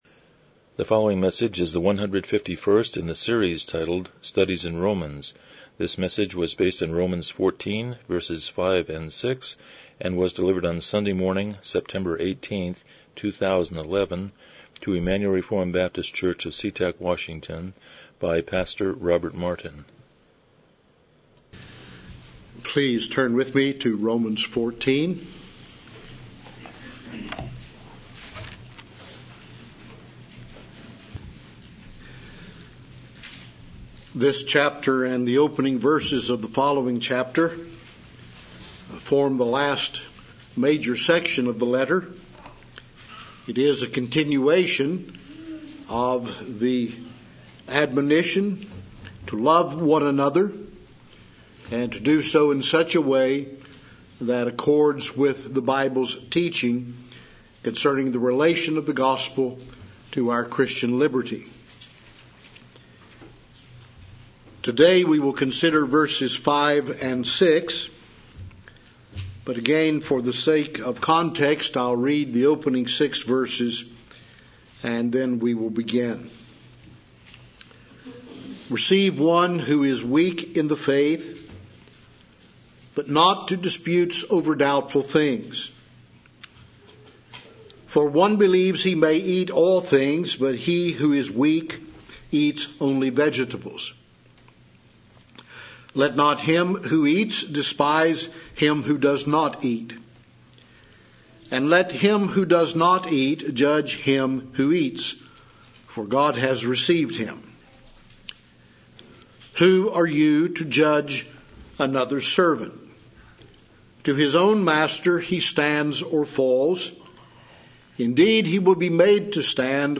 Romans 14:5-6 Service Type: Morning Worship « 129 Chapter 25.5-6